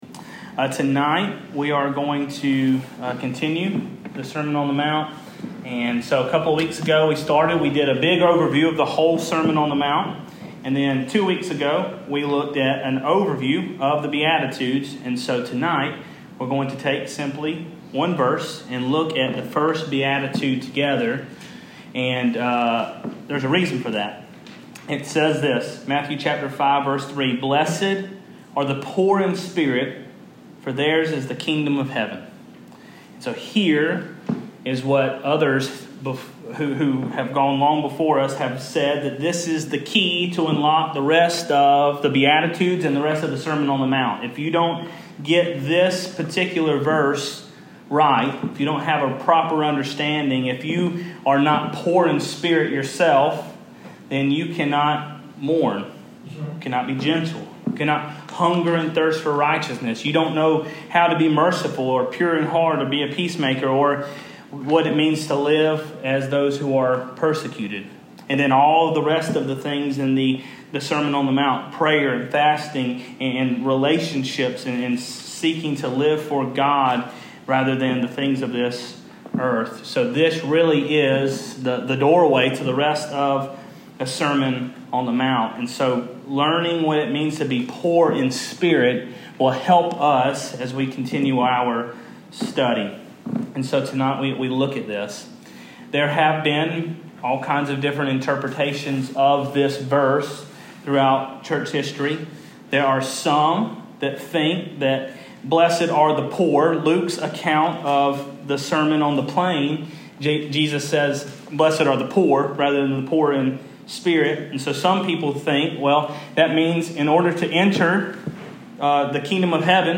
Sermon Audio | Lowrys First Baptist Church